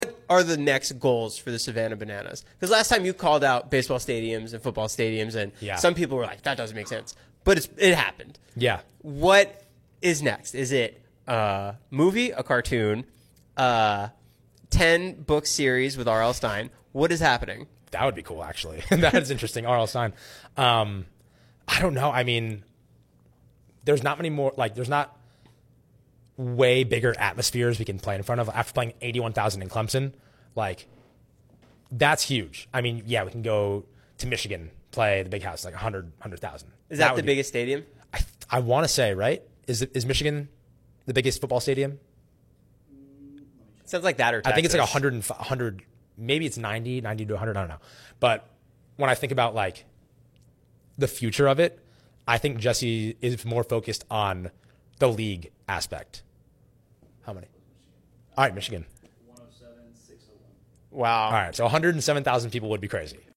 to a sold out crowd at a baseball stadium